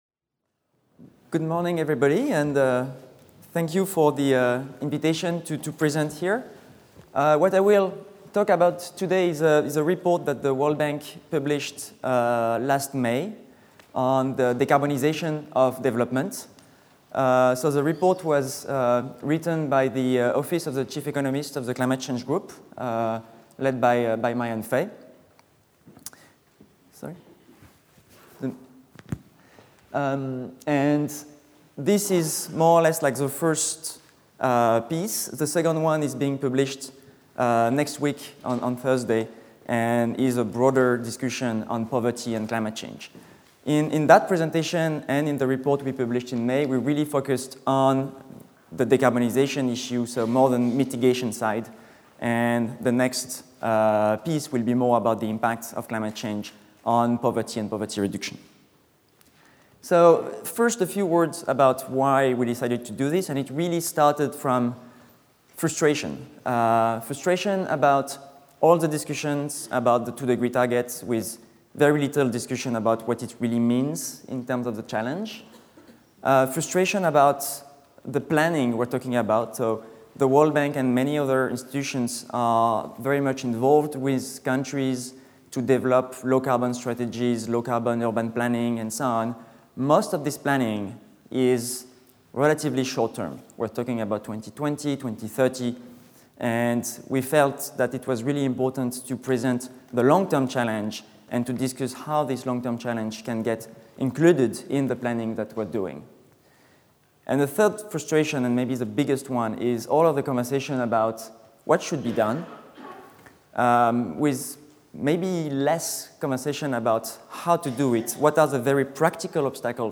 Sauter le player vidéo Youtube Écouter l'audio Télécharger l'audio Lecture audio Interprétation simultanée en direct en français.